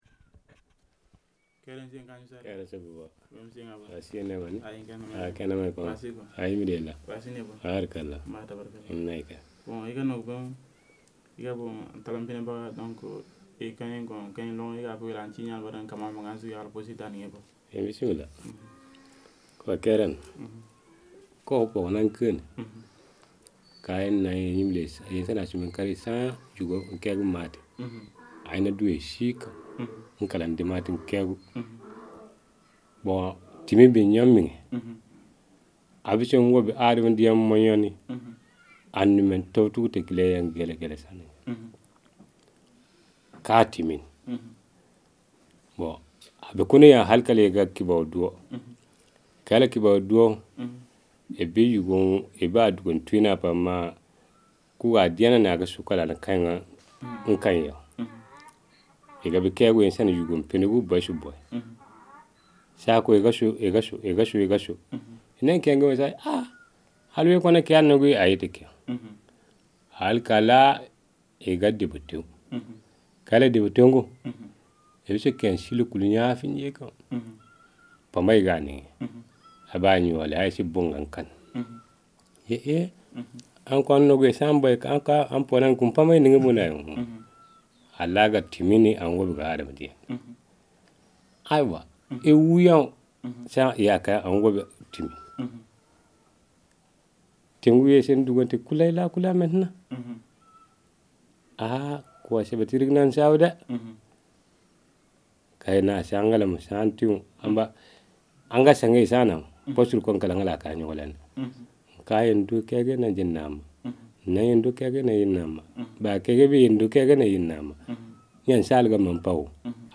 • field recordings in mp3 format made with digital microphone in Namagué village, Mali.